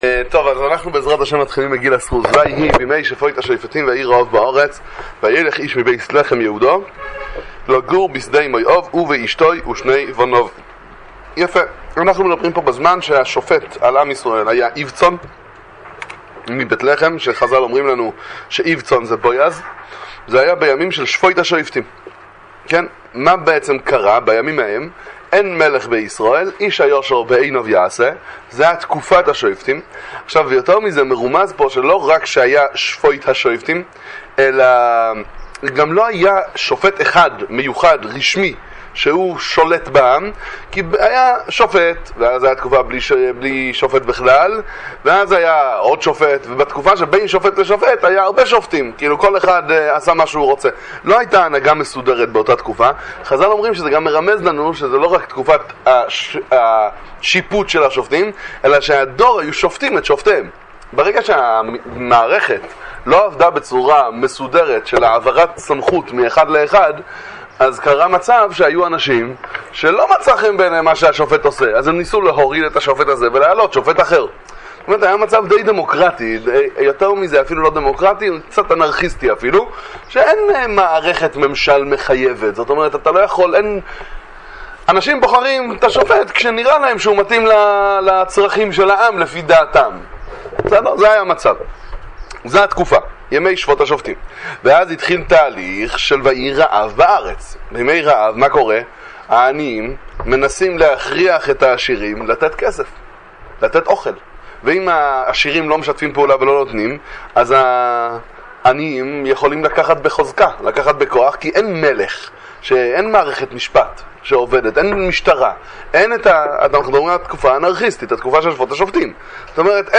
שיעור בנביאים וכתובים עם פירוש המלבי"ם, שיעורי תורה לחג השבועות, דברי תורה מגילת רות